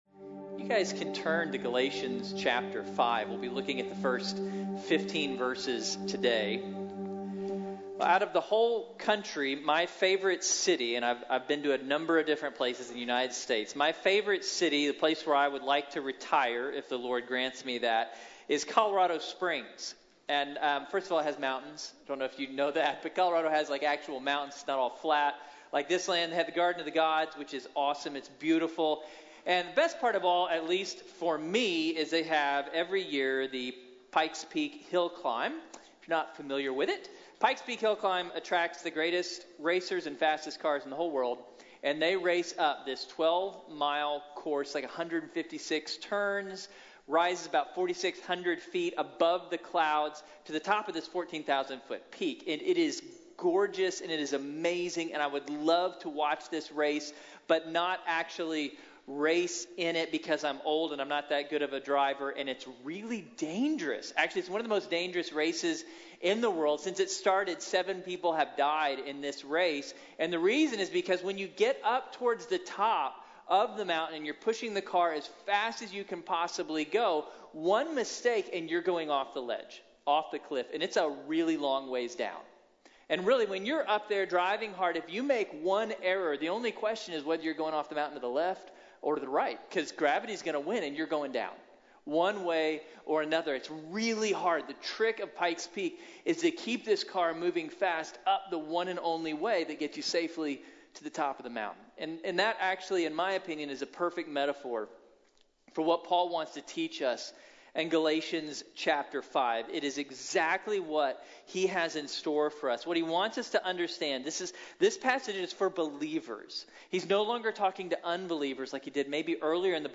Free to Love | Sermon | Grace Bible Church